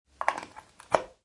Download Free Powerpoint Sound Effects